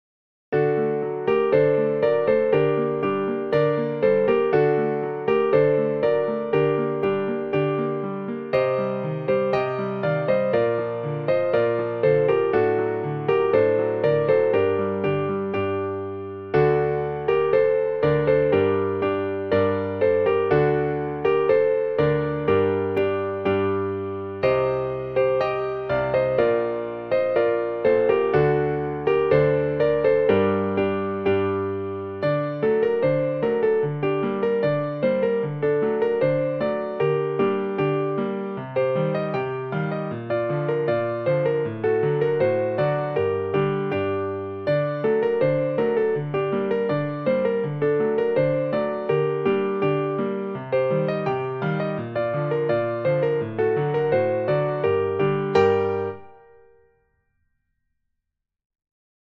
a Russian Folk Song - for piano